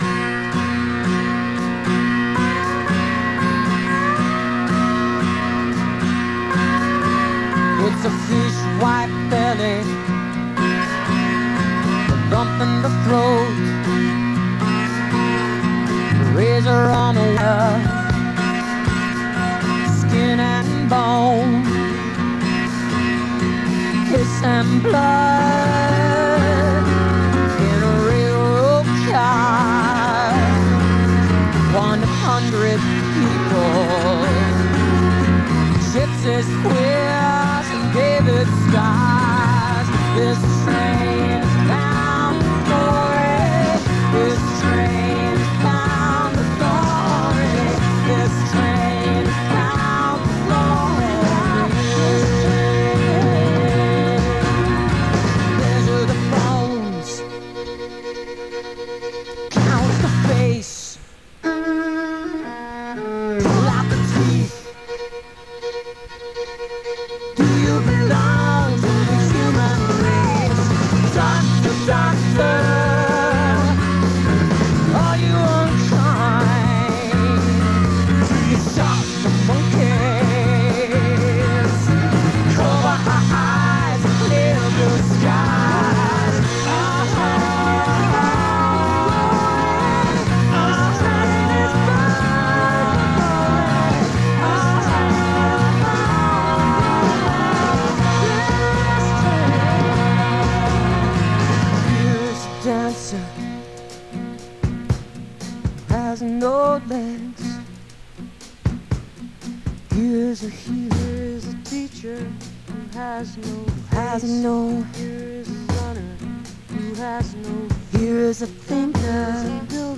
(album version)